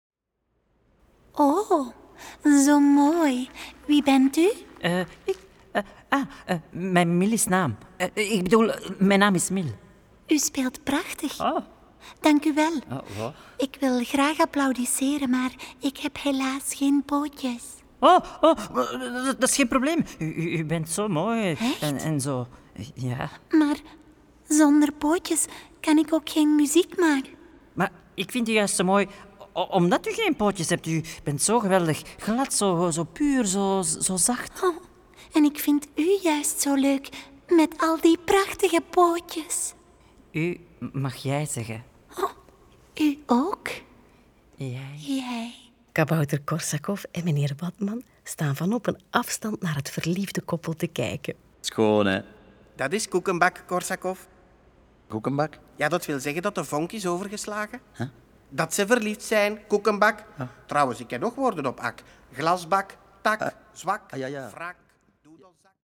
Kabouter Korsakov is een reeks luisterverhalen voor avonturiers vanaf 4 jaar.